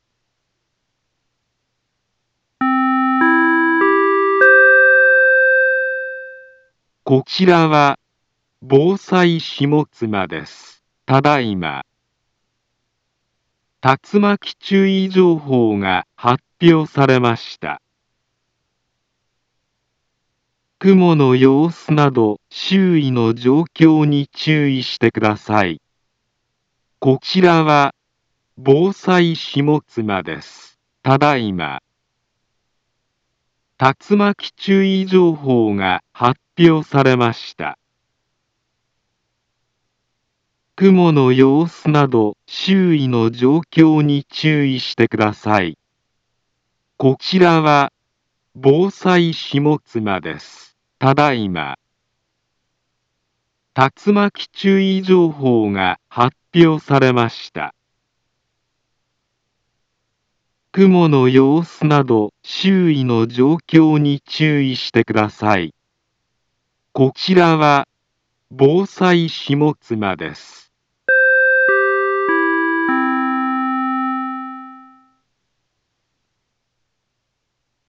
Back Home Ｊアラート情報 音声放送 再生 災害情報 カテゴリ：J-ALERT 登録日時：2023-09-20 18:24:59 インフォメーション：茨城県南部は、竜巻などの激しい突風が発生しやすい気象状況になっています。